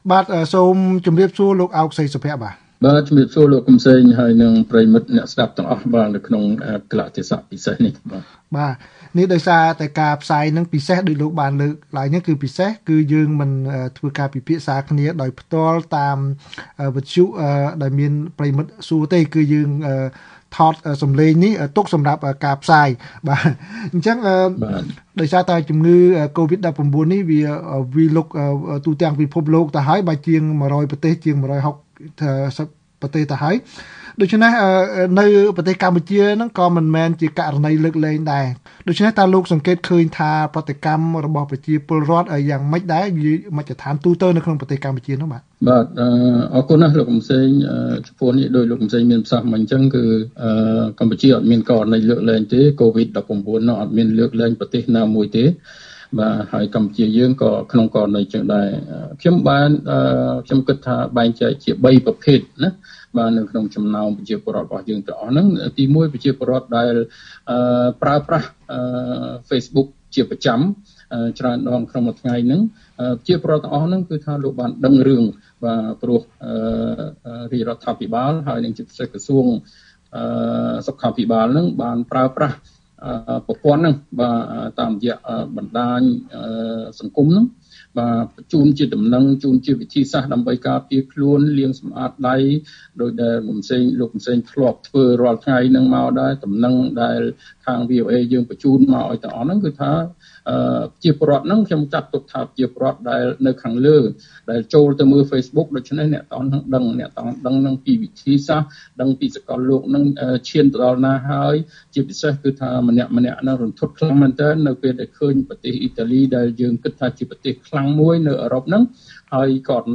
បទសម្ភាសន៍VOA៖ អ្នកជំនាញថា កង្វះព័ត៌មានធ្វើឱ្យពលរដ្ឋនៅមិនទាន់យល់ដឹងពីគ្រោះថ្នាក់ជំងឺកូវីដ១៩នៅឡើយ